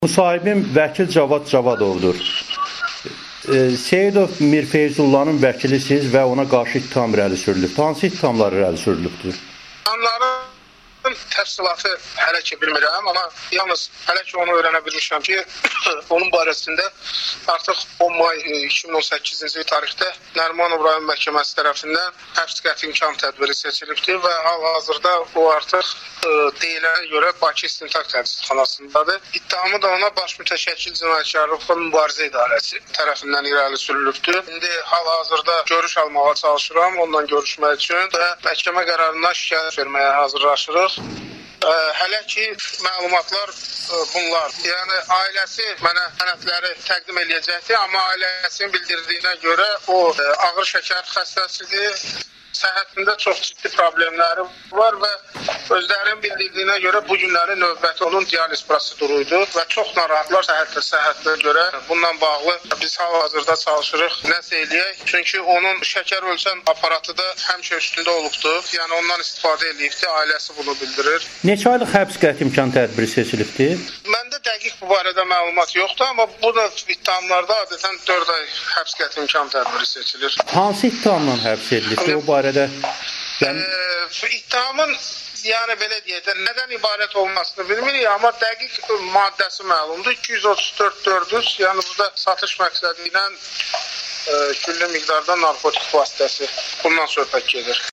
Amerikanın Səsinə müsahibəsi